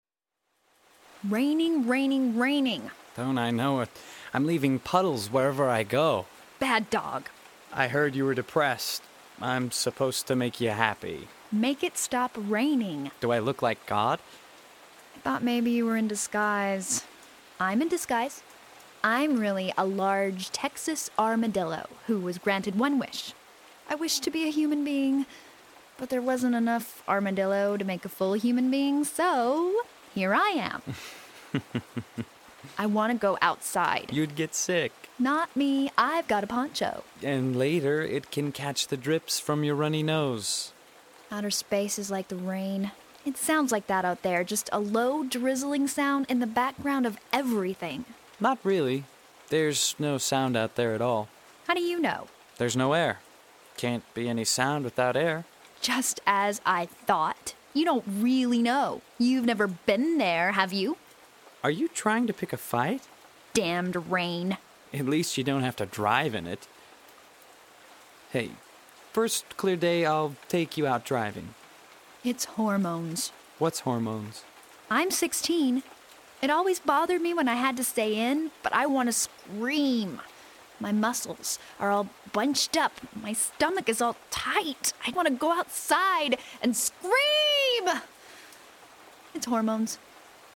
Download portions of the scripts or listen to audio samples of the original cast.